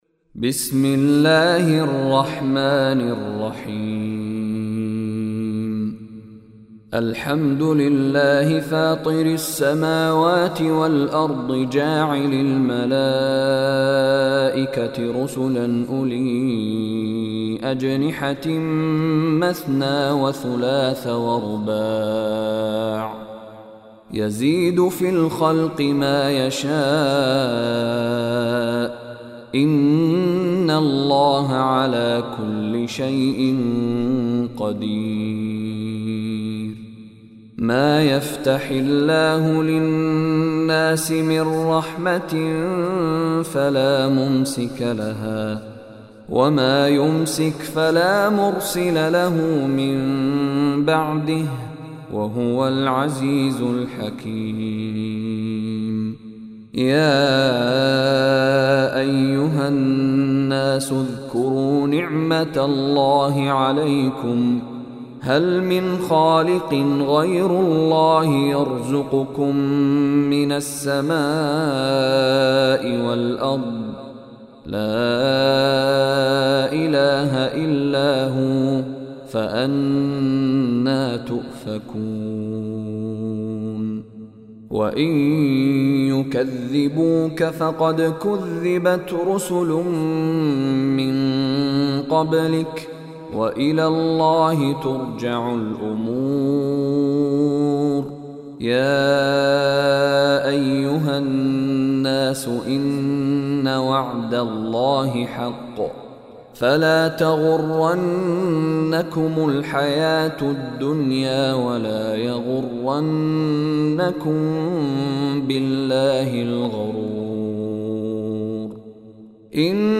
Surah Fatir Recitation by Sheikh Mishary Rashid
Listen online and download beautiful Quran tilawat / recitation of Surah Fatir in the beautiful voice of Sheikh Mishary Rashid Alafasy.